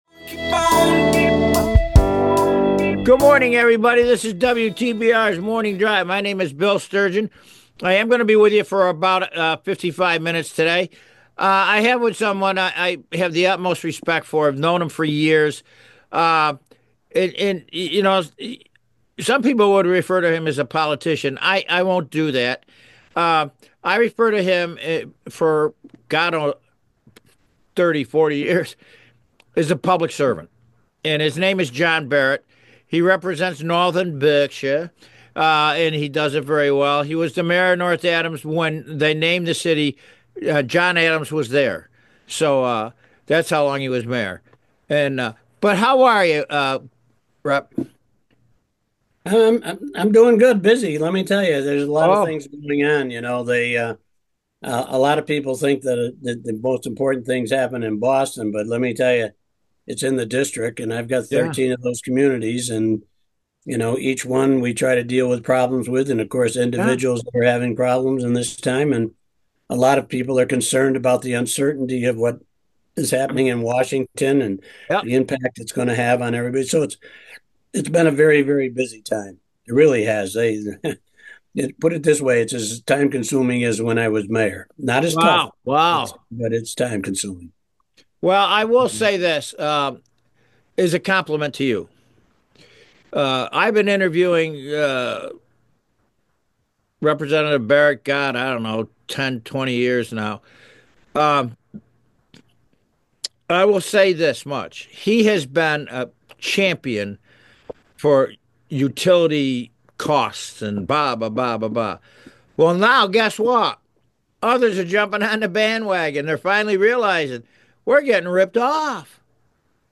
special guest State Representative John Barrett